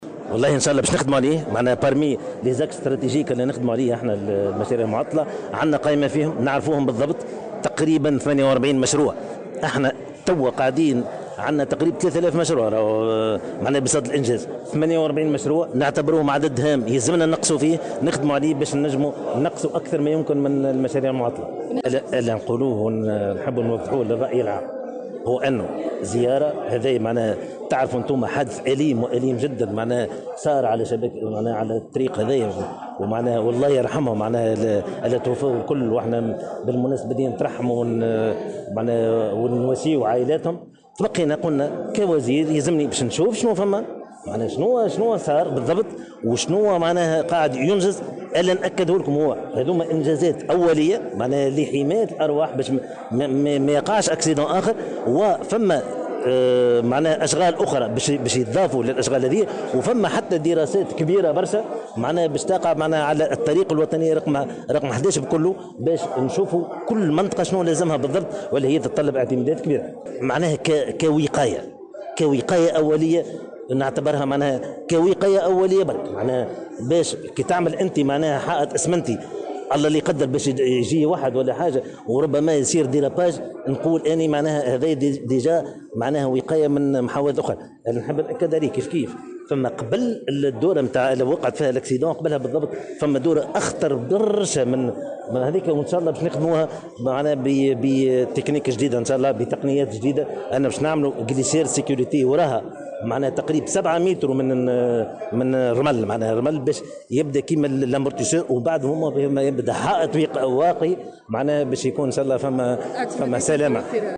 وأضاف في تصريح لمراسلة "الجوهرة أف أم" على هامش مناقشة ميزانية الوزارة بالبرلمان، أنه يجب العمل على تلافي الإشكاليات التي تحول دون تنفيذ هذه المشاريع والإسراع في إنجازها.